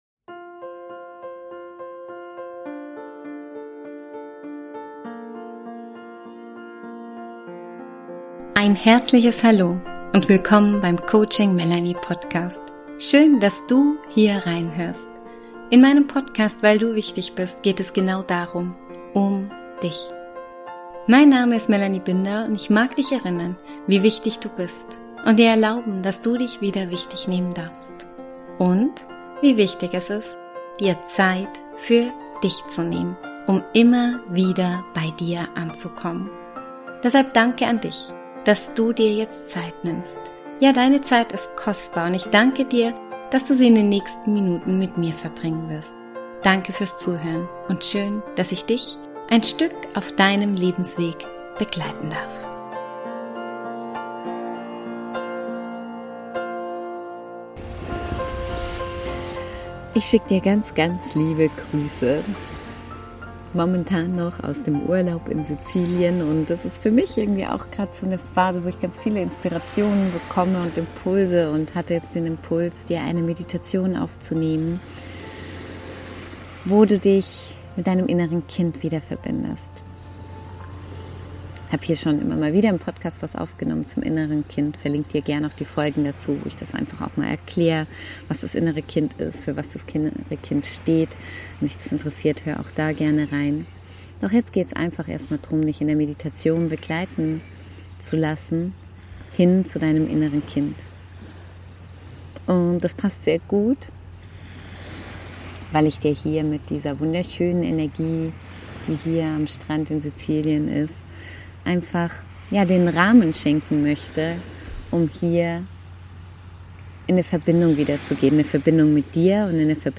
In dieser besonderen Meditation lade ich dich ein auf eine heilsame Reise ans Meer – zu deinem inneren Kind. Begleitet vom sanften Rauschen der Wellen darfst du dich erinnern, fühlen, loslassen und neu verbinden.
Diese Meditation habe ich für dich an einem besonderen Ort aufgenommen: direkt am Meer in Sizilien, umgeben vom Klang der Wellen, mit nackten Füßen im warmen Sand und ganz in Verbindung mit meinem inneren Kind und der Kraft der Natur.